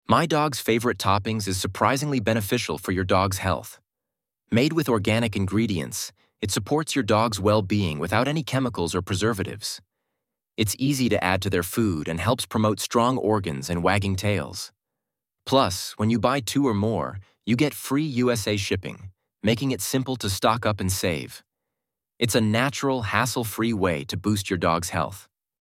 Voiceover video with b-roll of happy dogs outdoors in summer, integrating tips on keeping your dog's organs healthy with nutrition and hydration advice. Includes mention of MyDogsFavoriteToppings as an easy nutrition boost, with brand color accents and text overlays '#1 best product for Dogs' Organs Health', 'Free shipping on orders of two or more in the USA', and the slogan 'Recharge your dog's health'.
57321-voiceover.mp3